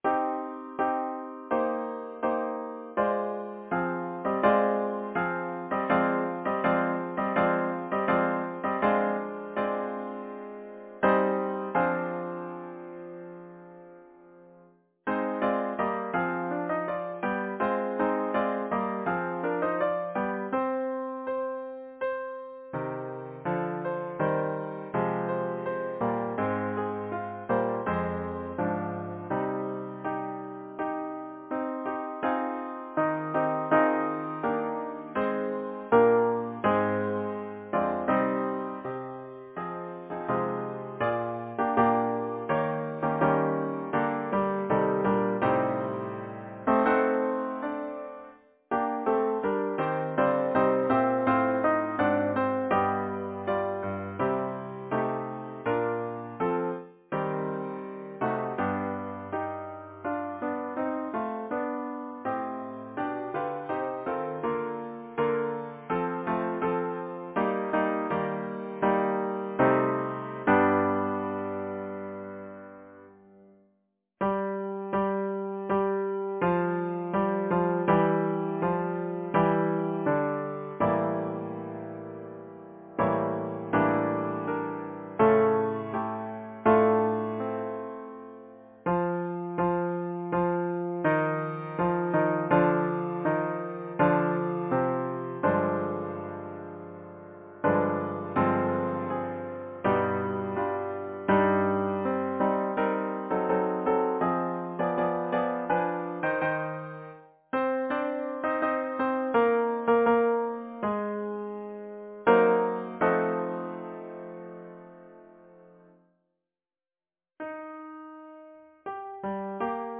Title: On time Composer: Charles Villiers Stanford Lyricist: John Milton Number of voices: 8vv Voicing: SATB.SATB Genre: Secular, Partsong
Language: English Instruments: A cappella